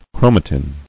[chro·ma·tin]